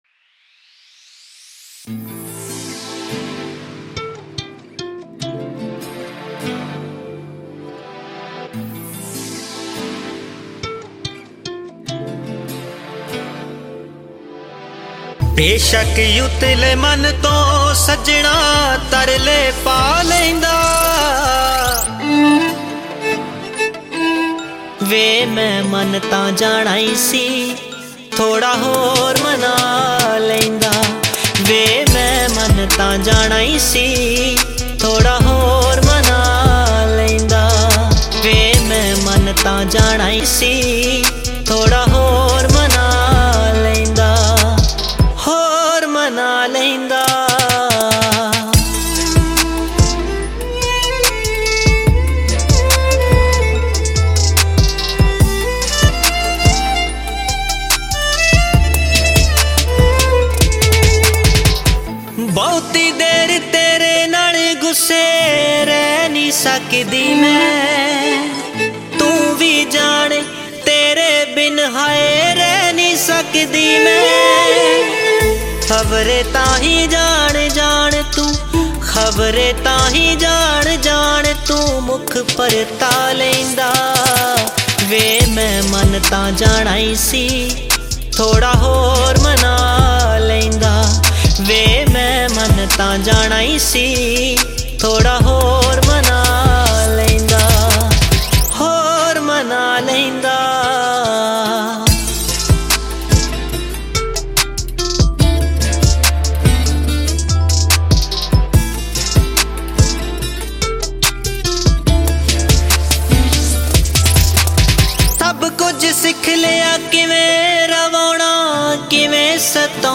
Latest Punjabi Songs